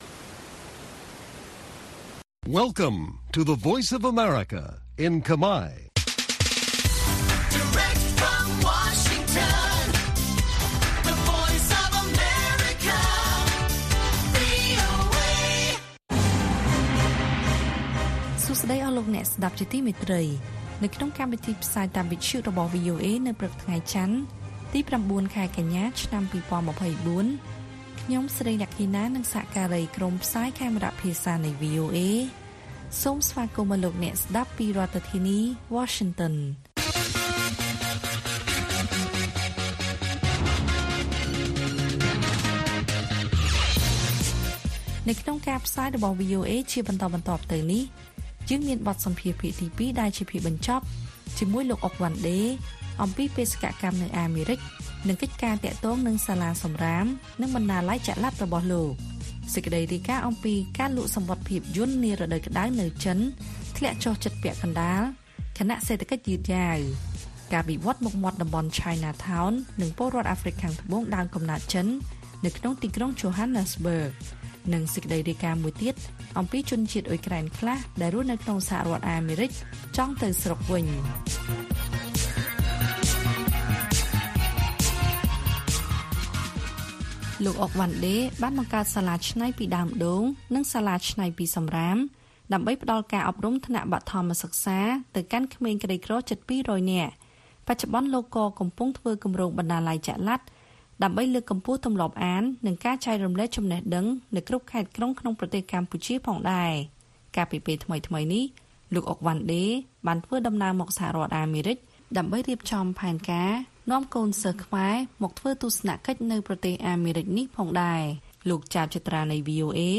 ព័ត៌មានពេលព្រឹក ៩ កញ្ញា៖ ការលក់សំបុត្រភាពយន្តនារដូវក្តៅនៅចិនធ្លាក់ចុះជិតពាក់កណ្តាល ខណៈសេដ្ឋកិច្ចយឺតយ៉ាវ